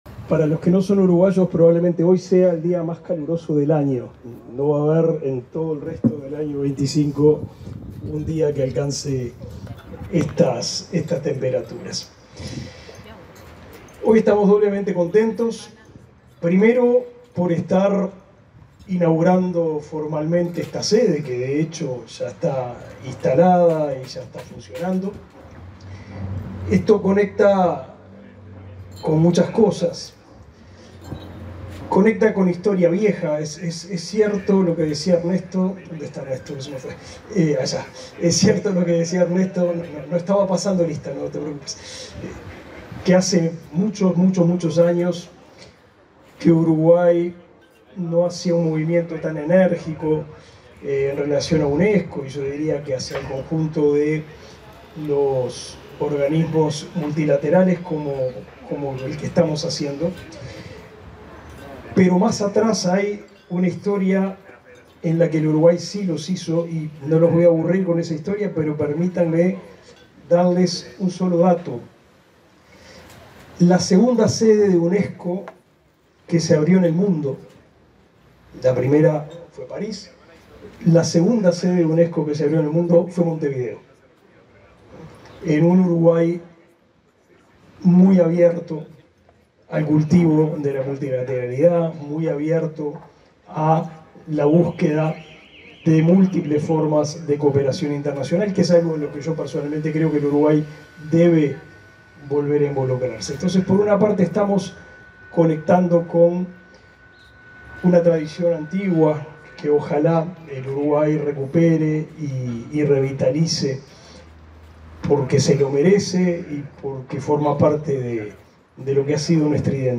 Palabras del ministro de Educación y Cultura, Pablo da Silveira
En el marco de la inauguración de una oficina regional de la Unesco para promover la transformación de la educación superior, se expresó el ministro